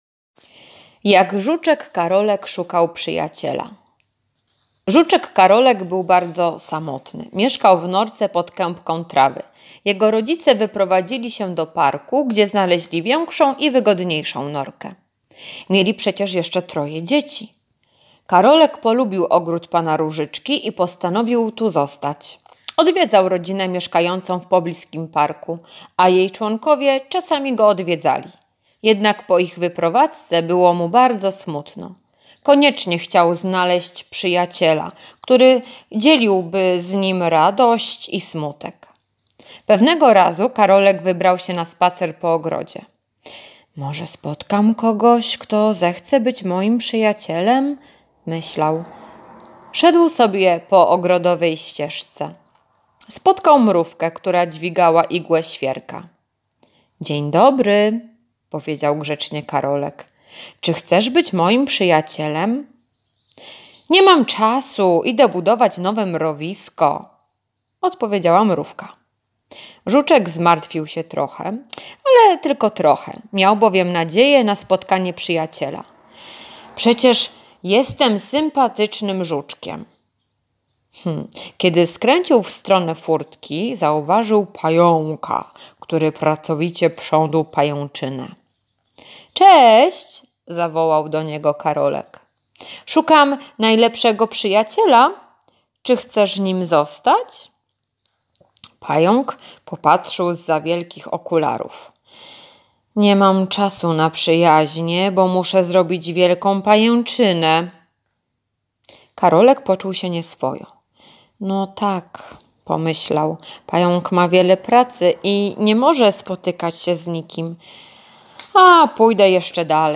czwartek- opowiadanie [8.32 MB] czwartek-zadanie [240.27 kB] czwartek- zadanie [159.98 kB] czwartek - ćw. dla chętnych - litera W, w [3.12 MB]